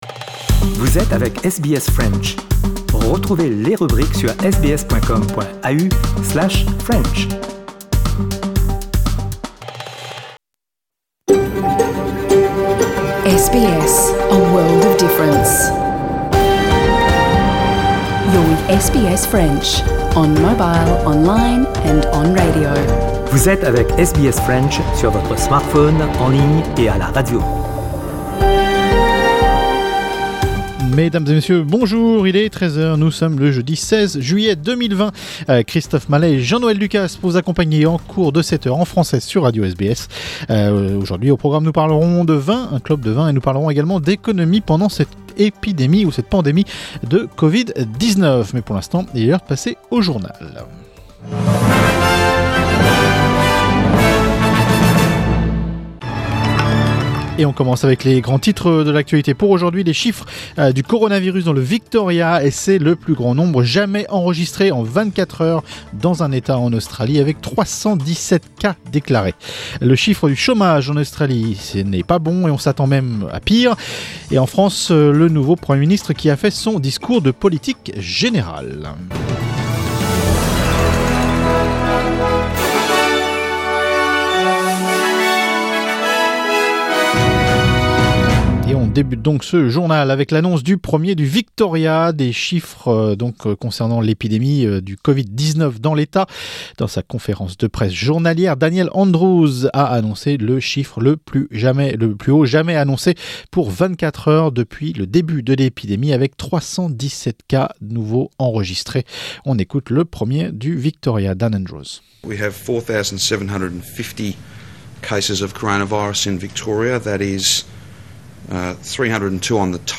L'emission live du 16/07/2020 en (presque) intégralité: Retrouvez notre programme en direct Mardi, Jeudi, Samedi et Dimanche sur SBS Radio 1